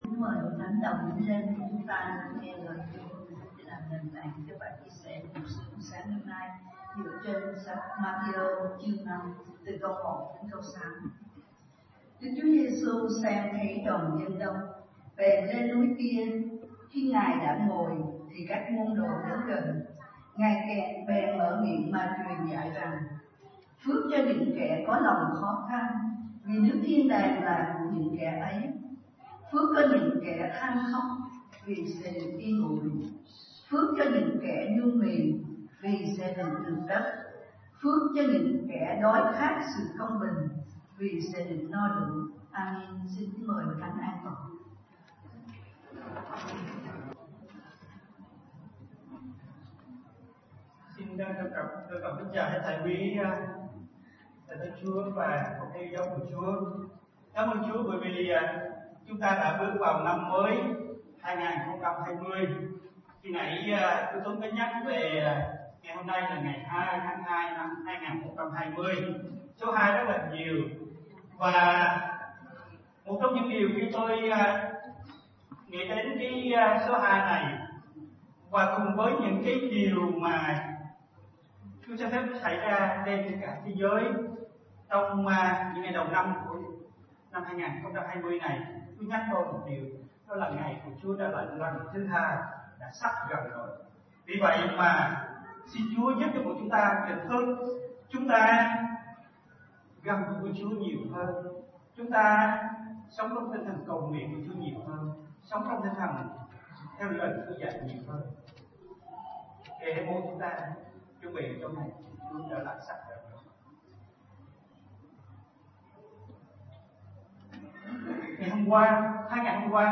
Bài Giảng